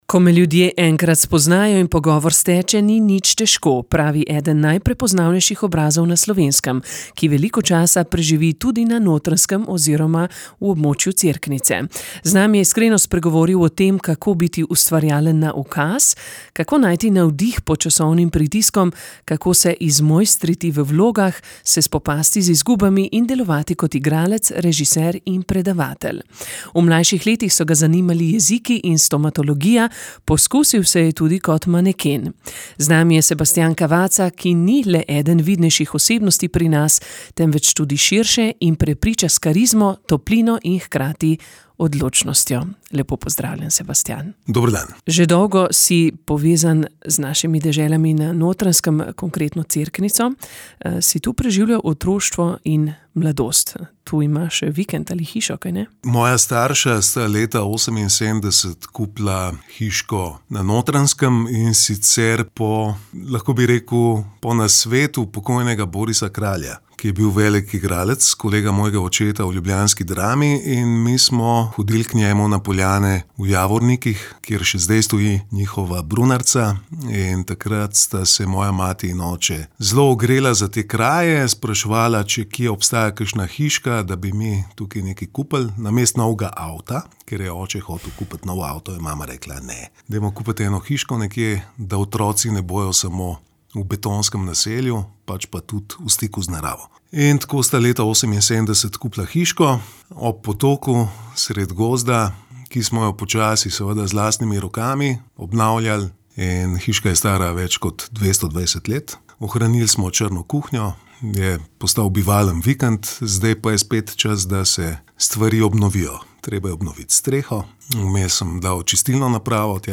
Z nami je iskreno spregovoril o tem, kako biti ustvarjalen na ukaz, kako najti navdih pod časovnim pritiskom, kako se izmojstriti v vlogah, se spopasti z izgubami in delovati kot igralec, režiser in predavatelj. V mlajših letih so ga zanimali jeziki in stomatologija, poskusil se je tudi kot maneken.